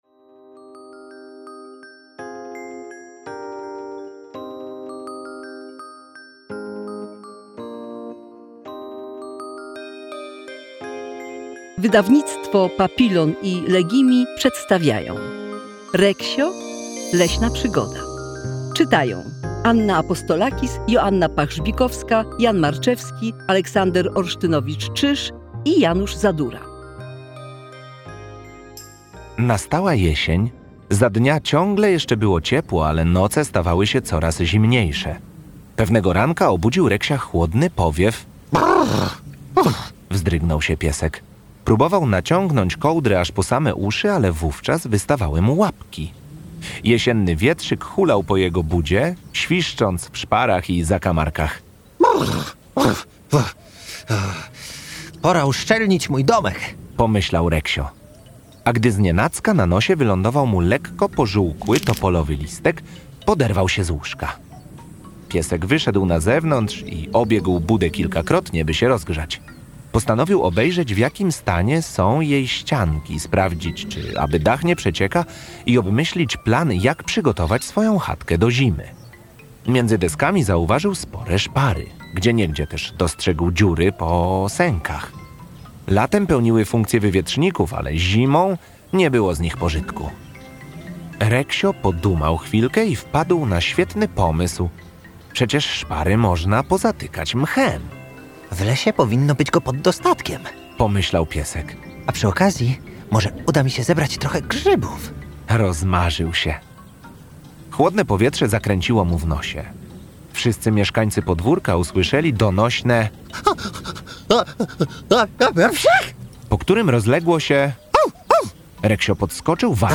Audiobook + książka Reksio. Leśna przygoda, Maria Szarf.